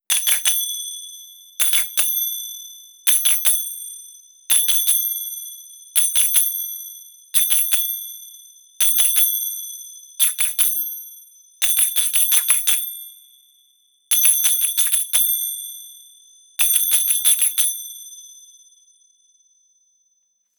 Golpes de crótalos siguiendo un ritmo 01
percusión
crótalo
golpe
ritmo